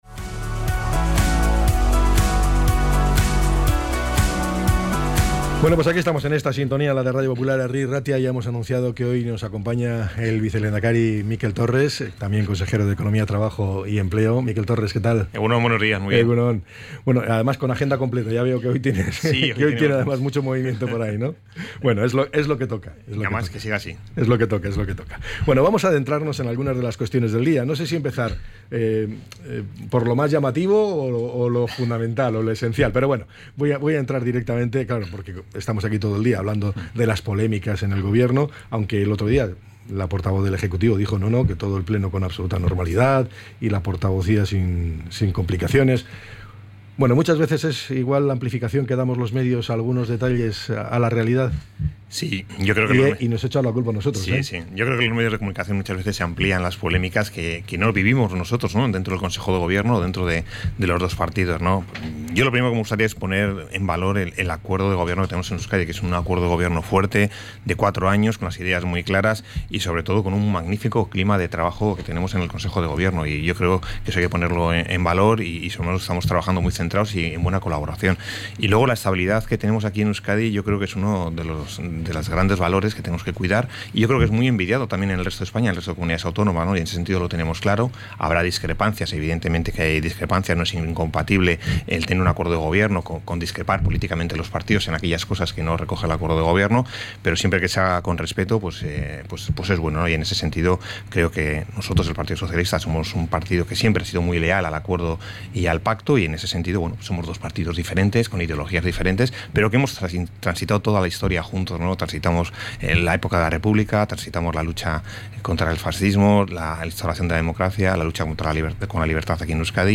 ENTREV.-MIKEL-TORRES.mp3